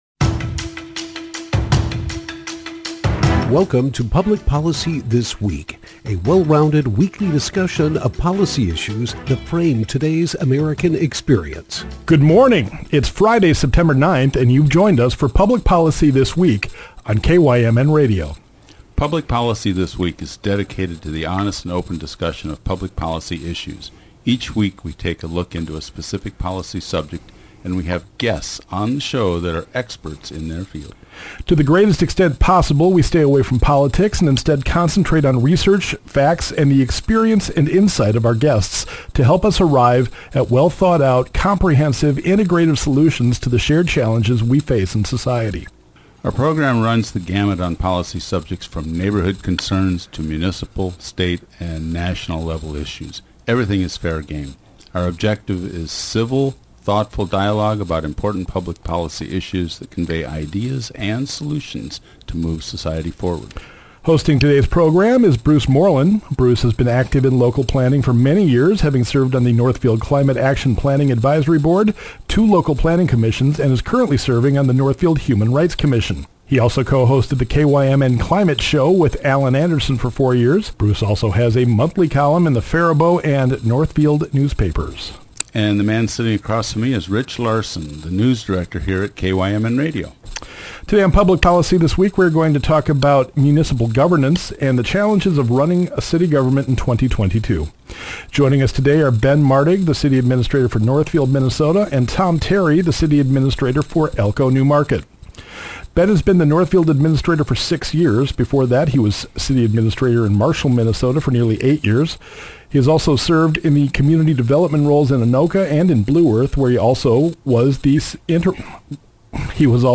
Public Policy This Week - Municipal governance with city administrators Tom Terry and Ben Martig 9/9/22